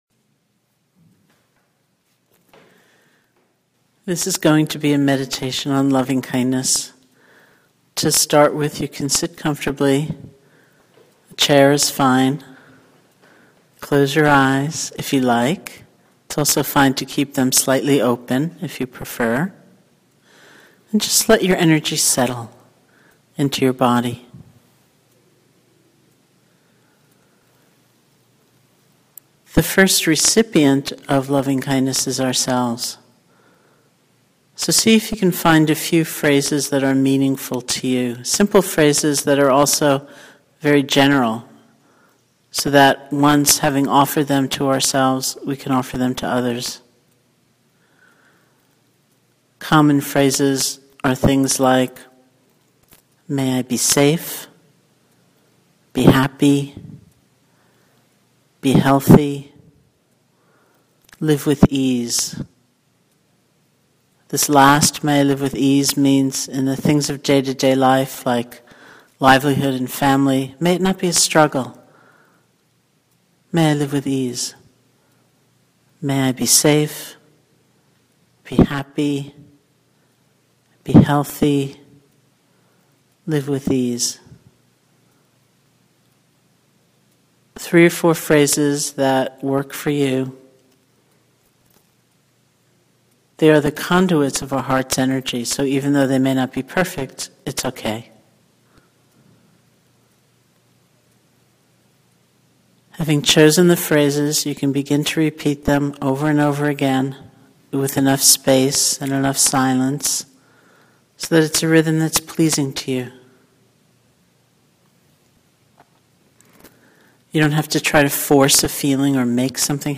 Guided meditations
loving_kindness_meditation_sharon_salzberg.mp3